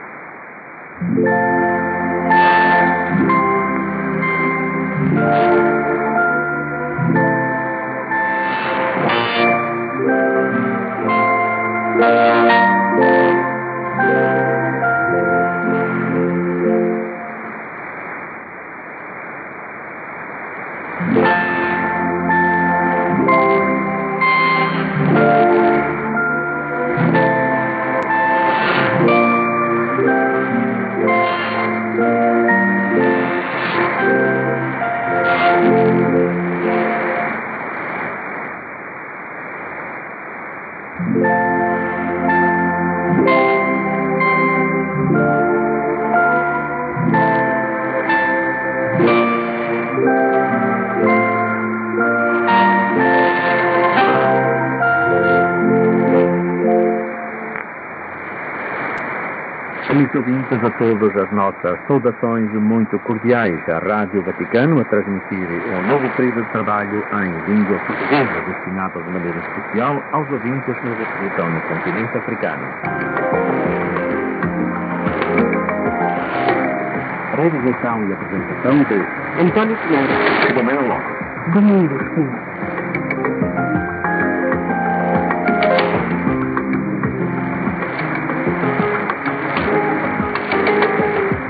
・このＨＰに載ってい音声(ＩＳとＩＤ等)は、当家(POST No. 488-xxxx)愛知県尾張旭市で受信した物です。
IS: interval signal
ID: identification announcement